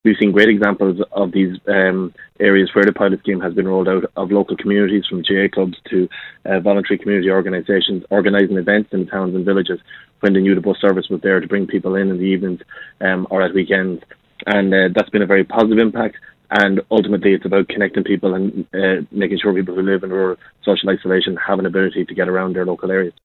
Fine Gael TD for Kildare South, Martin Heydon, is calling for the pilot to be extended into 2019, and says it makes a huge difference to people in rural areas: